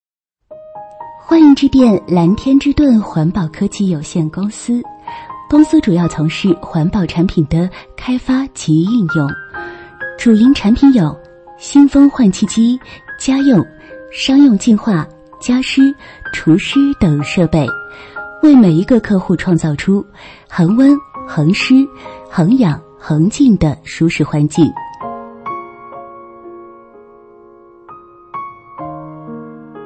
提供交互式语音应答菜单，客户电话呼入时可根据语音提示进入相应的服务。
当用户播打时，将听到提示音“您好，欢迎致电400*******，话费积分查询及密码服务请按1，业务咨询办理请按2，如需帮助请按0。”